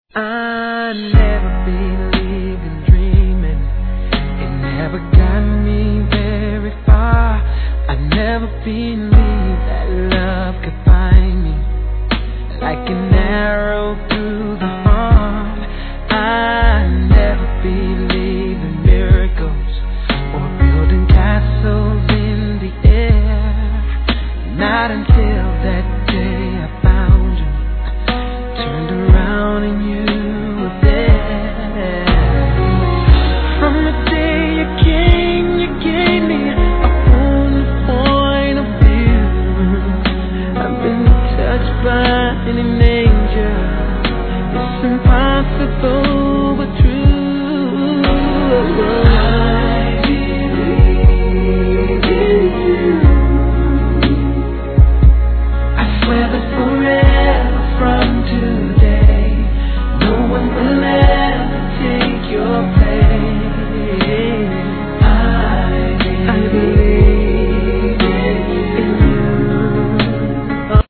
HIP HOP/R&B
SLOW〜MIDテンポがやはり聴き所!!